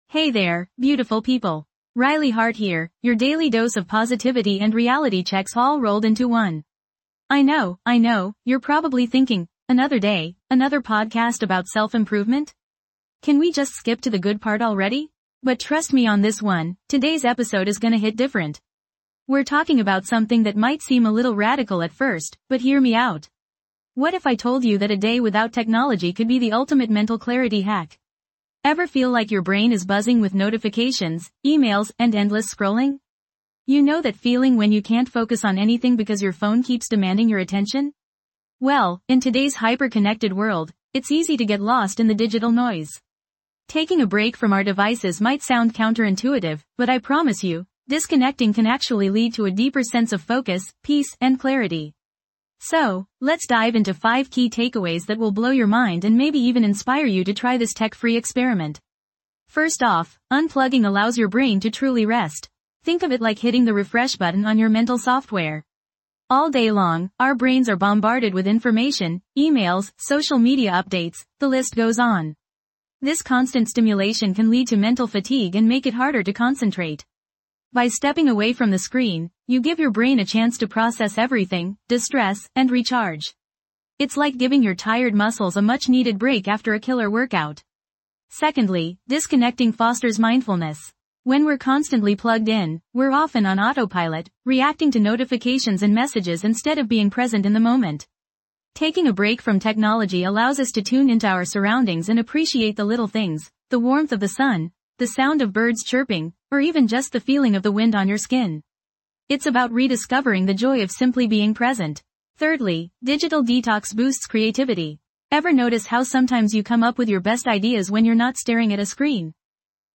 This podcast offers a sanctuary of calm amidst the chaos of everyday life. With gentle affirmations and soothing guidance, we guide you through a 5-minute daily reset, helping you cultivate inner peace, reduce stress, and find moments of tranquility throughout your day.